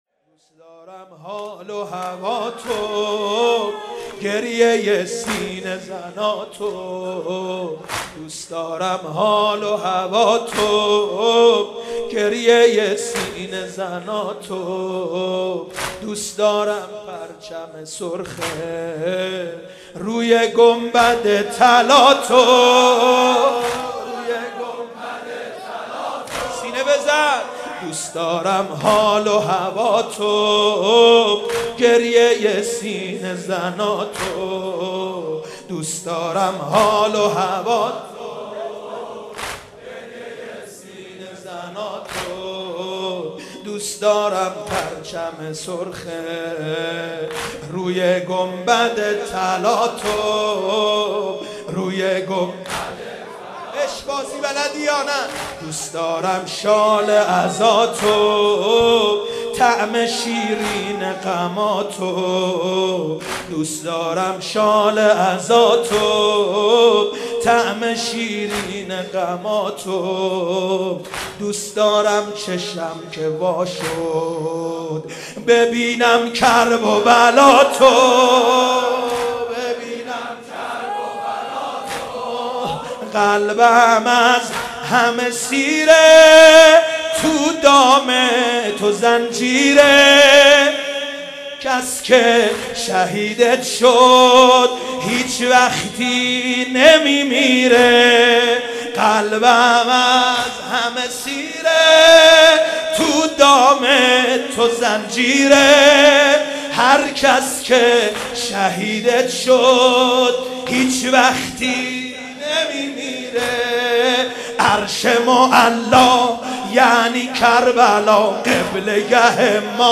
محرم 94